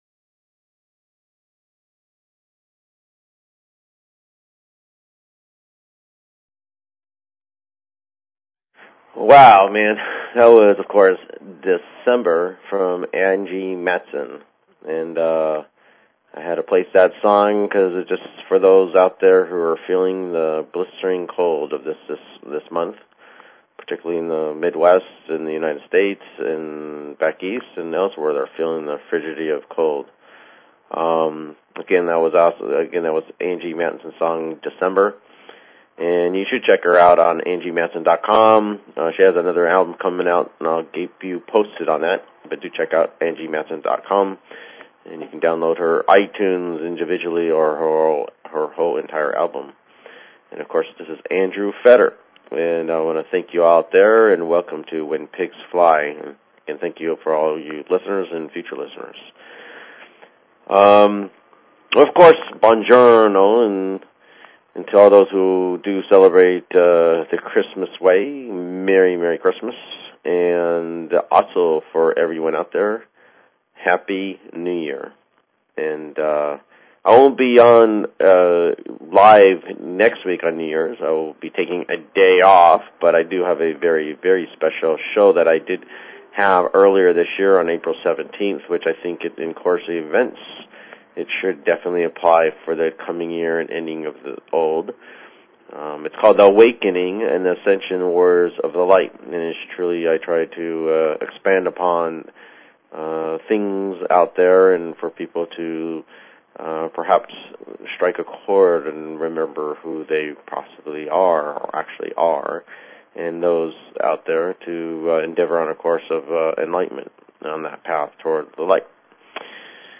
Talk Show Episode, Audio Podcast, The_Antimatter_Radio_Show and Courtesy of BBS Radio on , show guests , about , categorized as